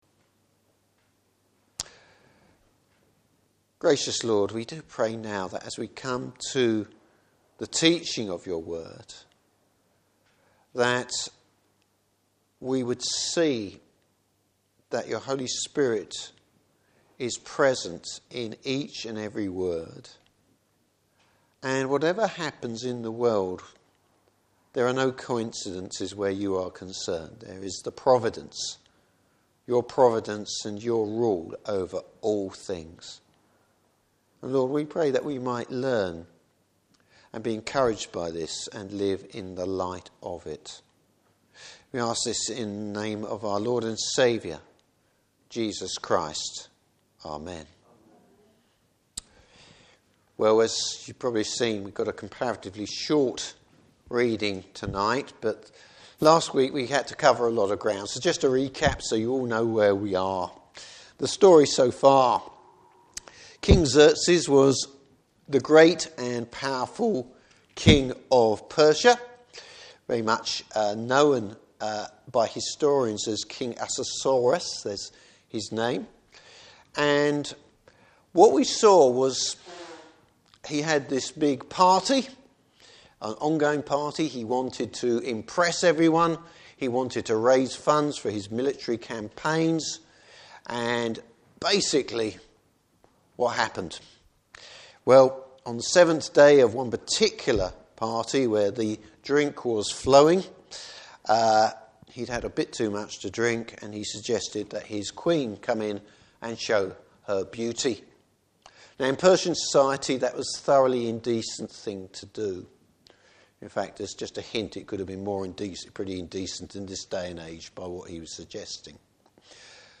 Service Type: Evening Service God working behind the scenes.